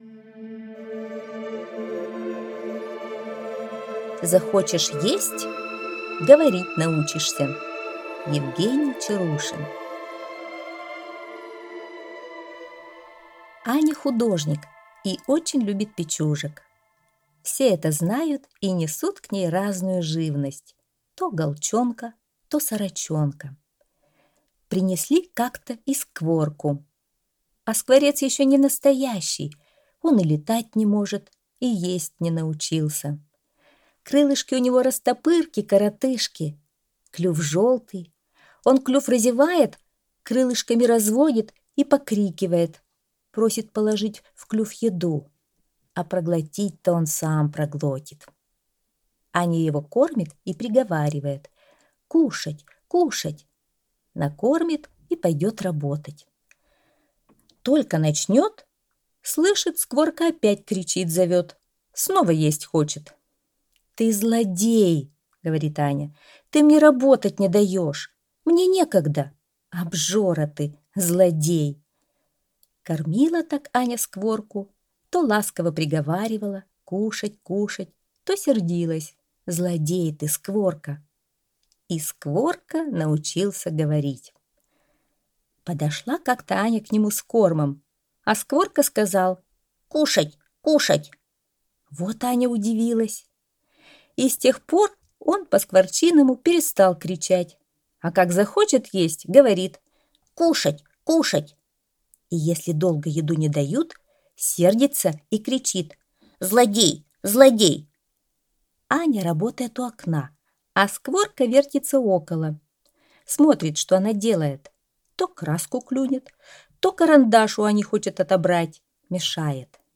Аудиорассказ «Захочешь есть»